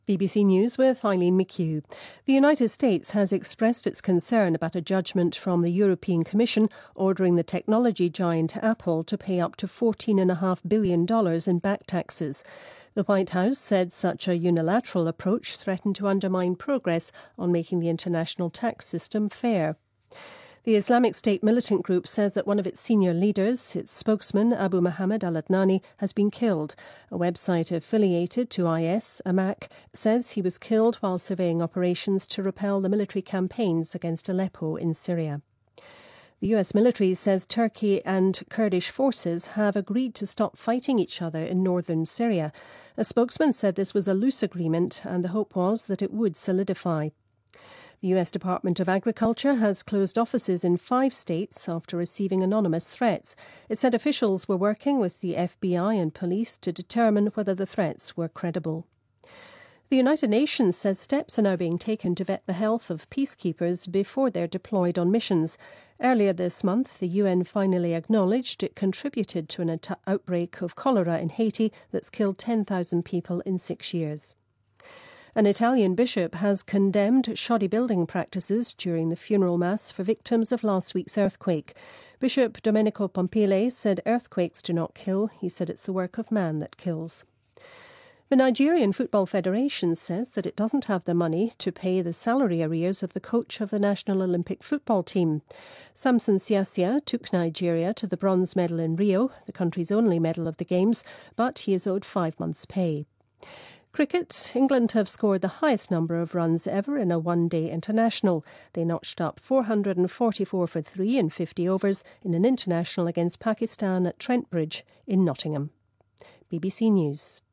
您的位置：BBC > BBC在线收听 > 9月新闻 > 苹果被判补缴145亿税费